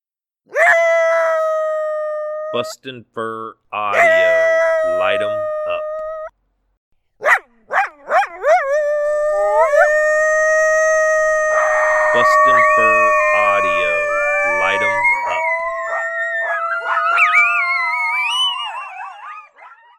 BFA's pack all howling together in this group howl that's sure to get a vocal response from the Coyotes you're calling to.